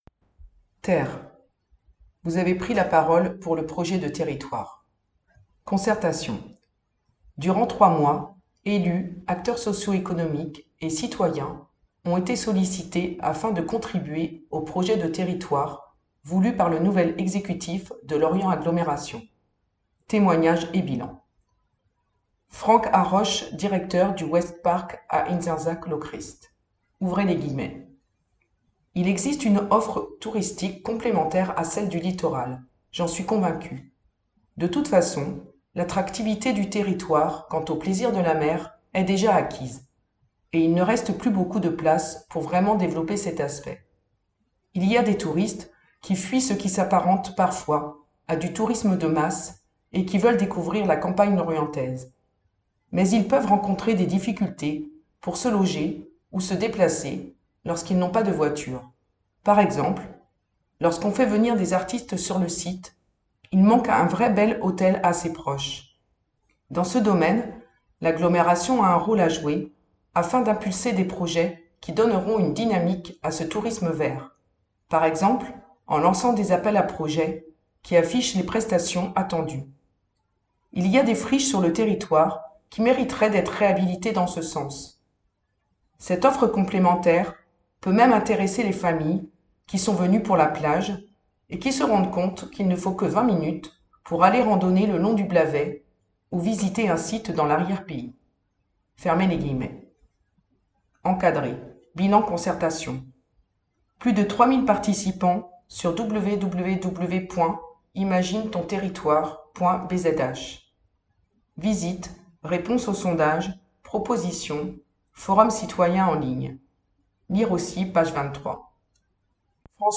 Version audio du magazine